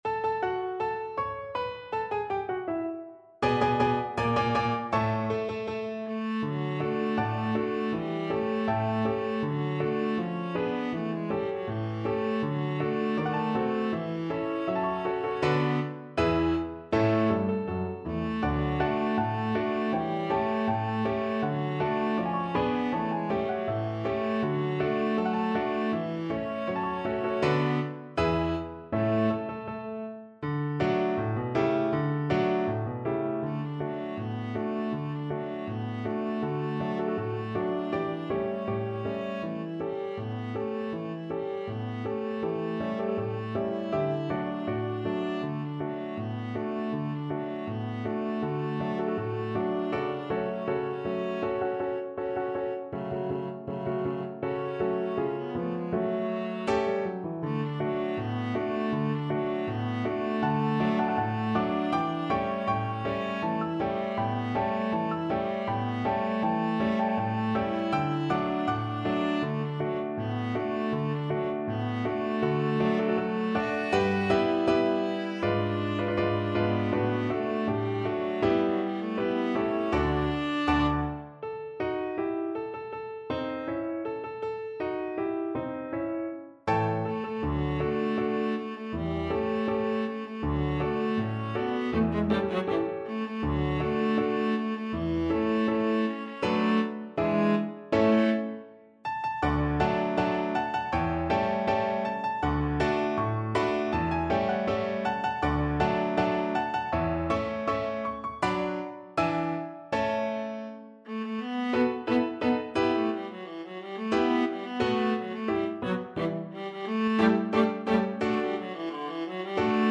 in the form of a popular jaunty march
2/2 (View more 2/2 Music)
Not Fast =80
Classical (View more Classical Viola Music)